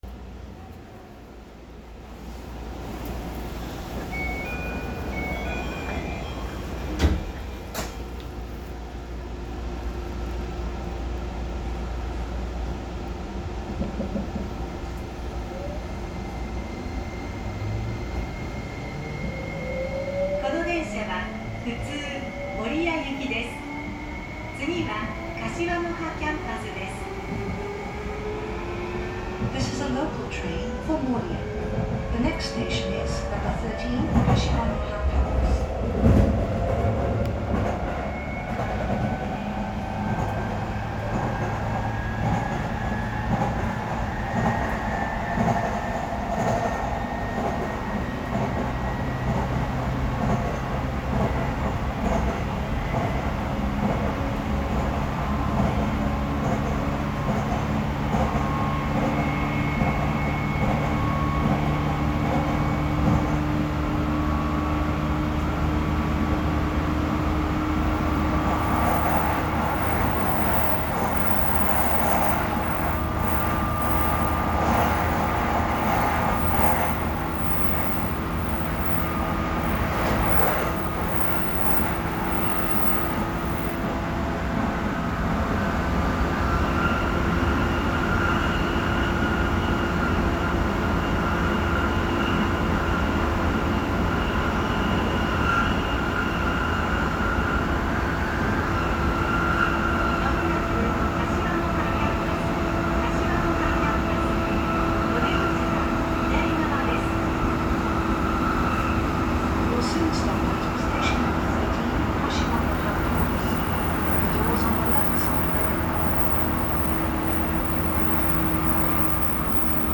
〜車両の音〜
走行装置は日立SiCとなりました。日立SiCとしては標準的な音で、停車の瞬間の音が特徴的と言えます。
3000_Otaka-Kashiwanoha.mp3